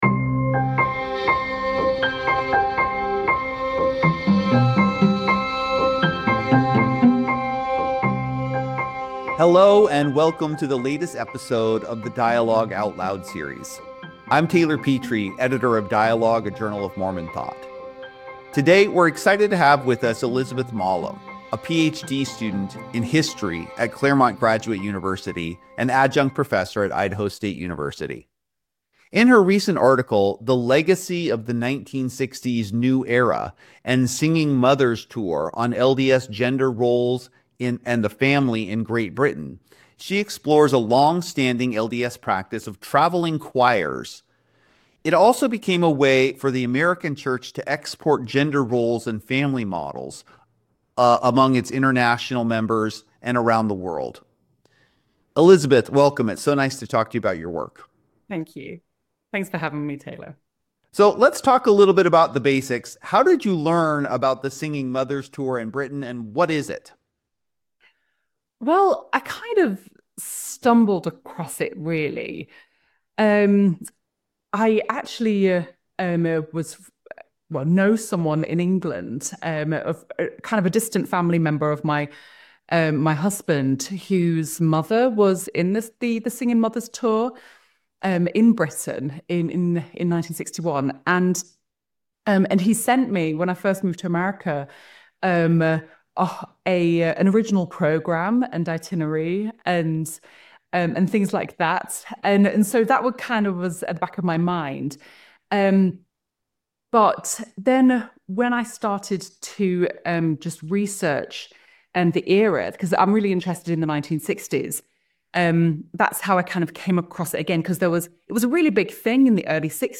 Claiming a New Era: A Conversation